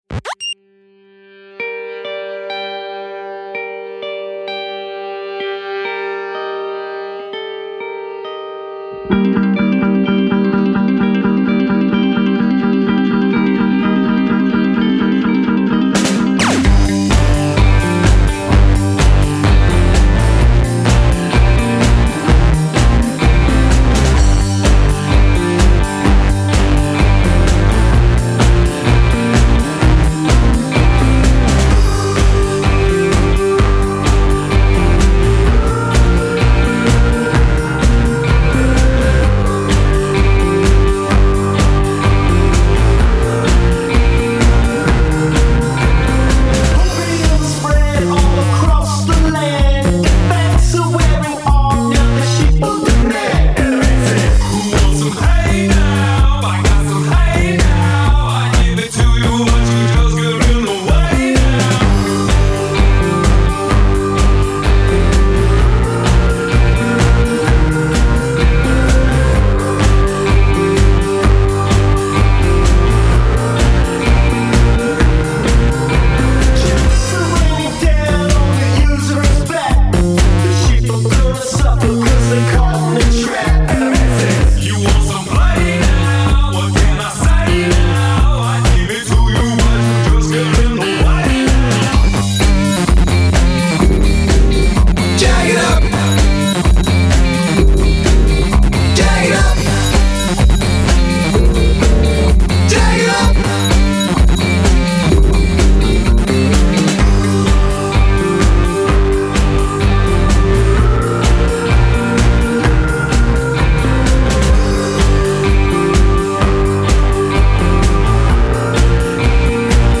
Genre: ProgPop and progressive rock.
vox, keys, gtr